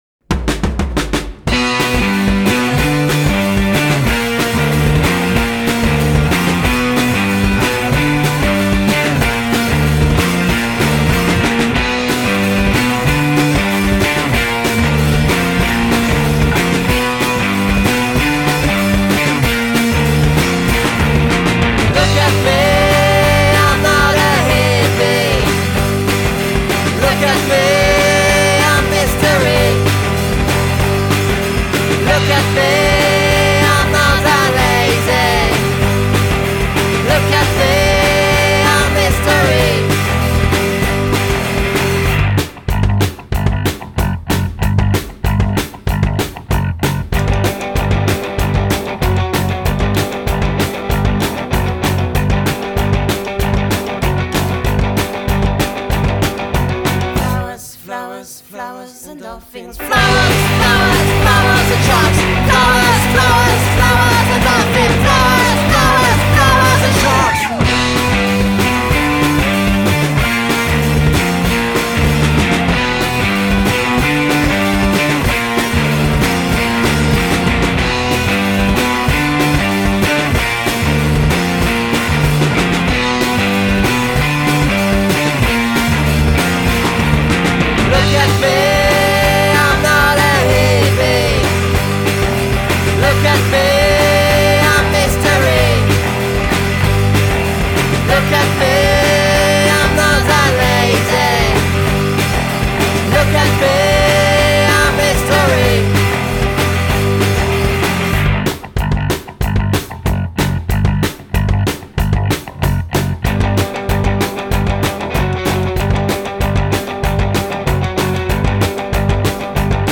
Énergie, fantaisie et rock grenier